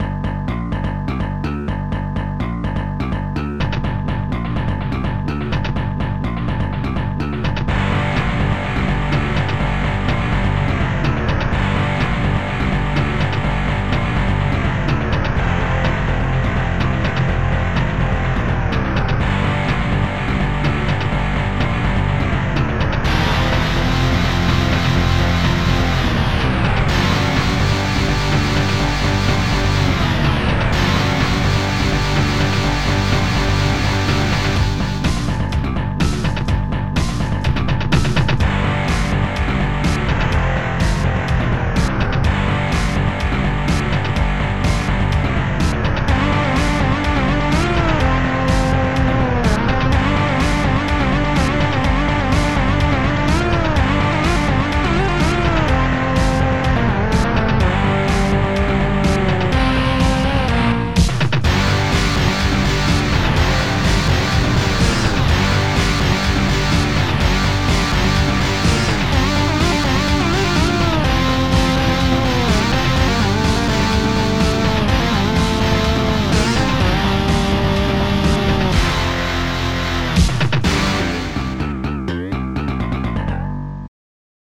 OctaMED Module